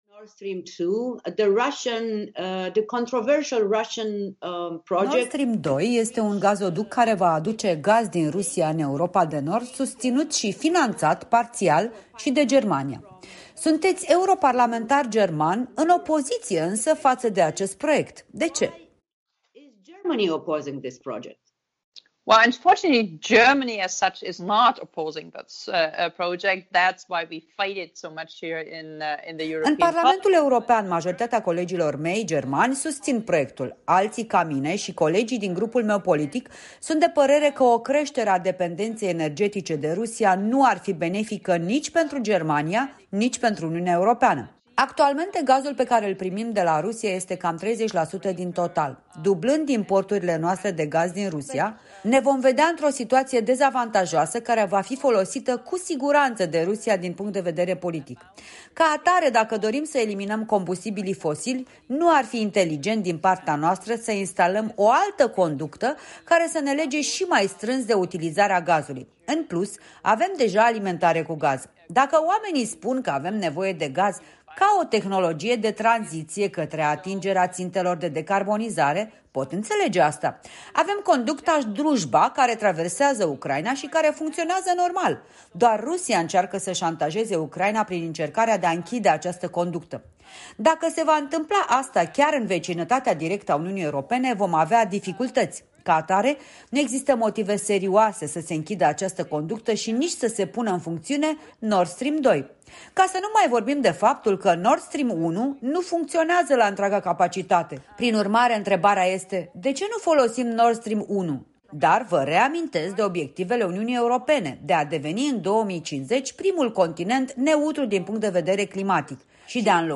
Interviu cu europarlamentara germană (Verzii) membră a Comisiei pentru Industria și Energie din Parlamentul European, un opozant vocal al proiectului Nord Stream 2